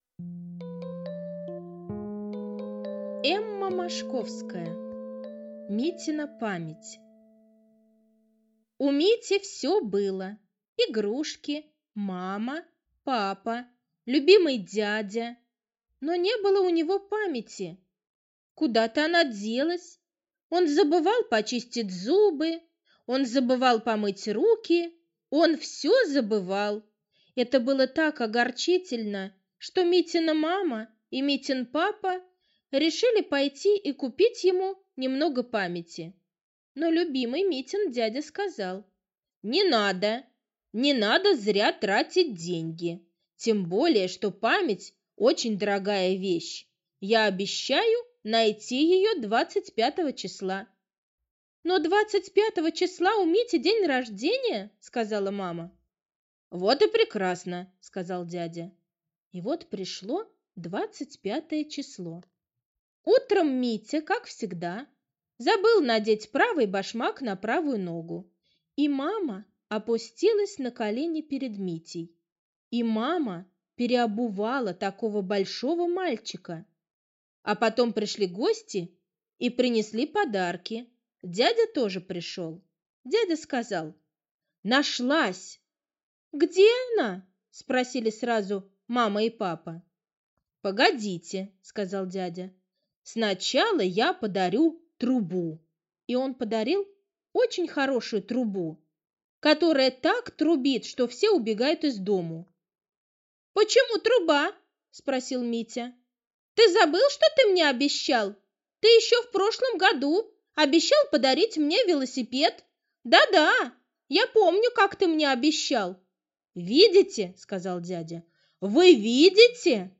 Митина память - аудиосказка Мошковской Э.Э. Сказка про мальчика Митю, который забывал почистить зубы, помыть руки и много других вещей.